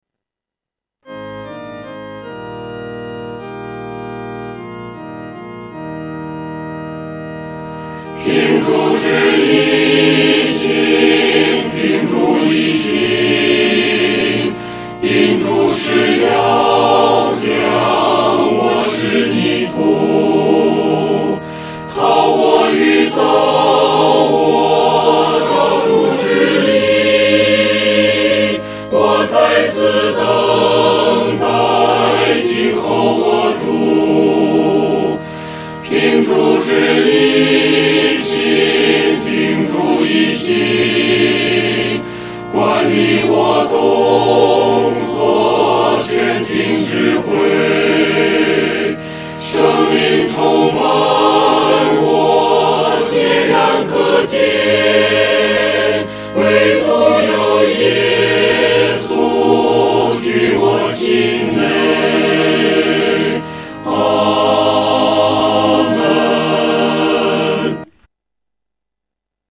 合唱
诗班在练习这首诗歌时，要清楚这首诗歌的音乐表情是虔敬、纯洁地。